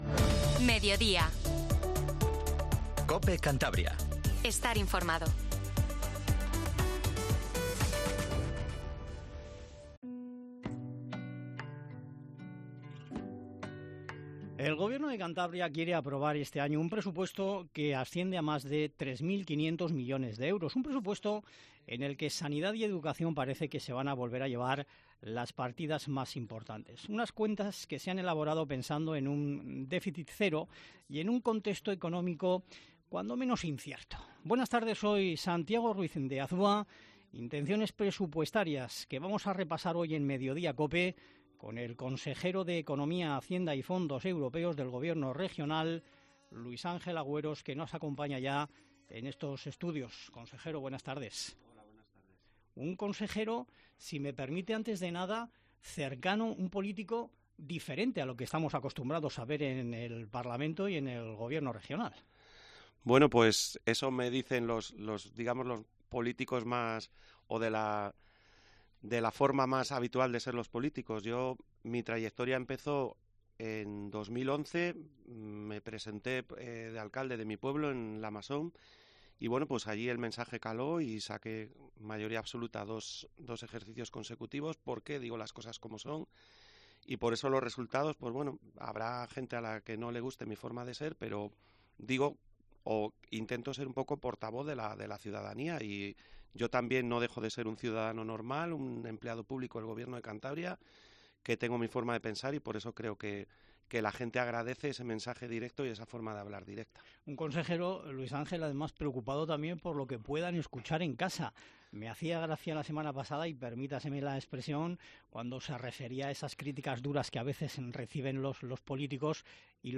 El titular de Economía, Hacienda y Fondos Europeos explica en COPE las claves de las cuentas para el año que viene en Cantabria
Luis Ángel Agüeros, consejero de Economía, Hacienda y Fondos Europeos